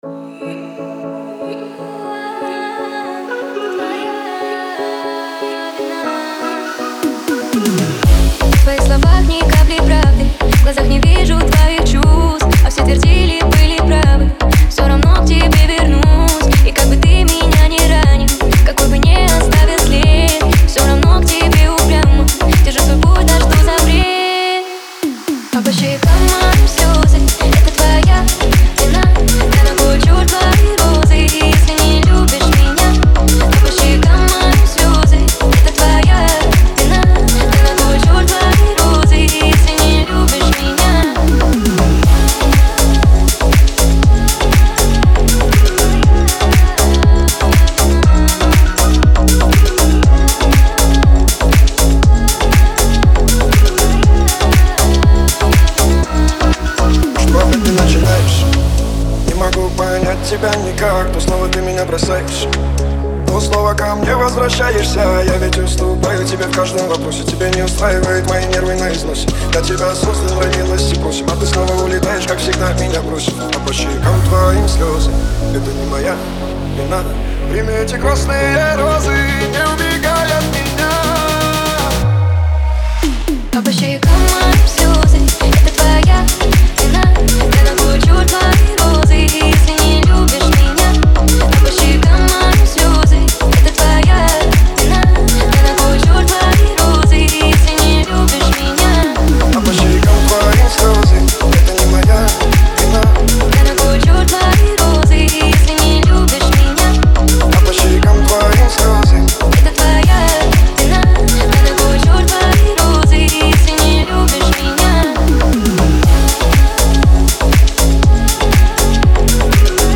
это трек в жанре поп